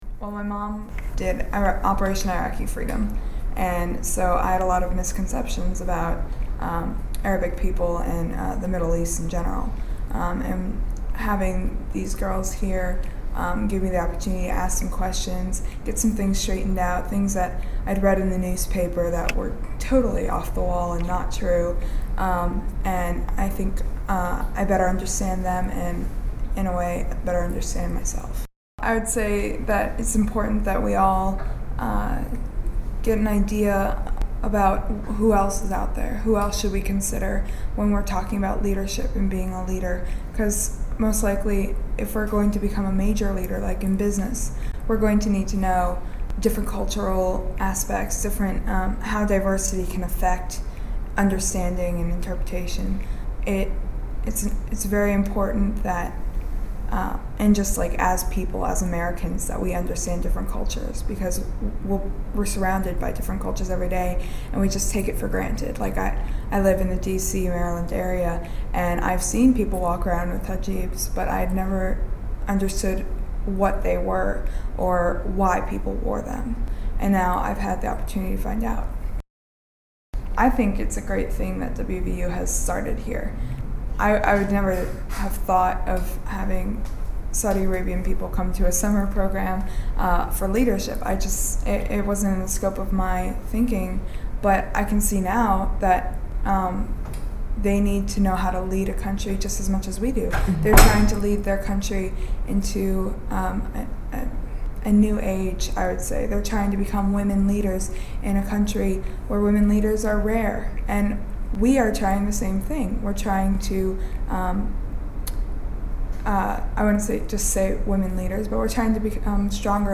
a WVU student